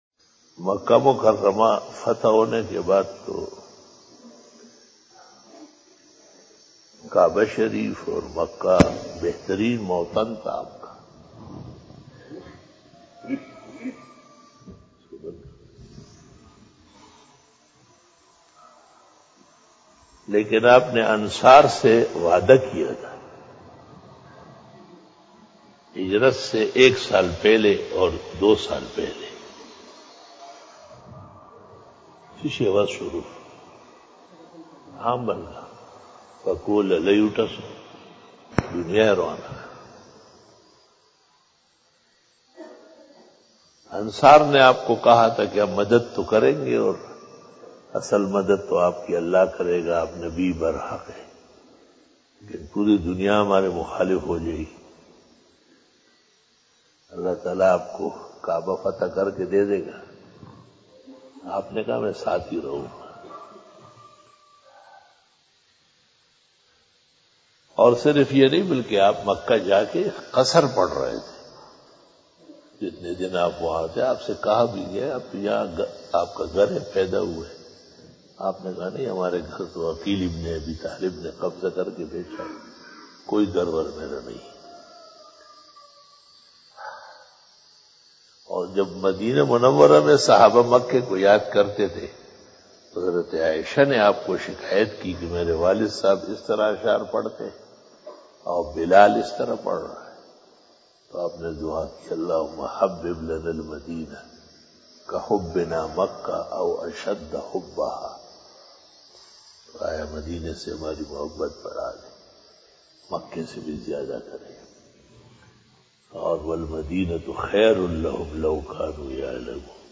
After Namaz Bayan
After Fajar Byan